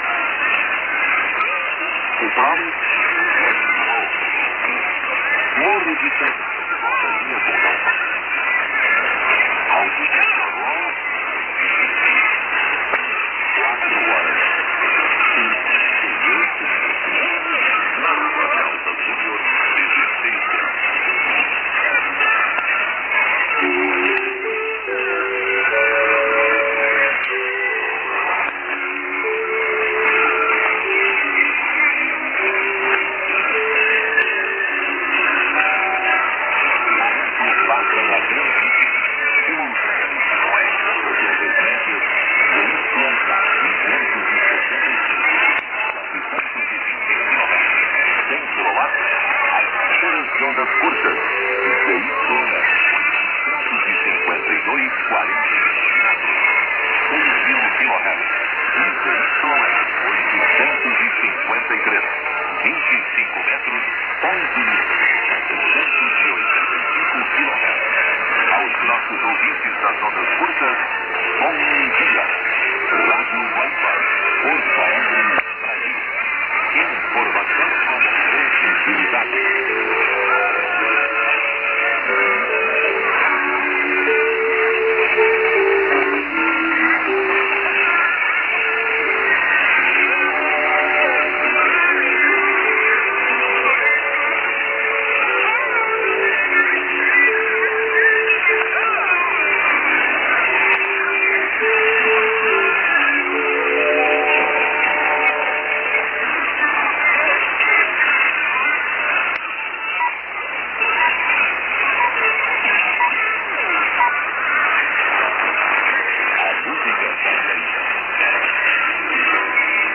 ->01'00":SKJ(man)->TS->　ＩＤは確認できませんが、